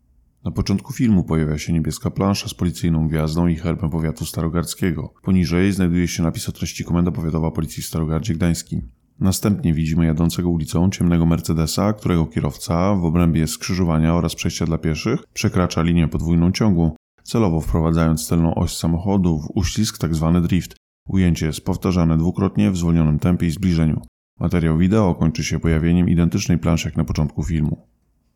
Pobierz plik Audiodeskrypcja_do_materialu_wideo_-_3000_zl_za_niewskazanie_driftujacego.mp3 (format mp3 - rozmiar 889.04 KB)